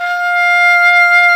Index of /90_sSampleCDs/Roland LCDP04 Orchestral Winds/WND_English Horn/WND_Eng Horn 2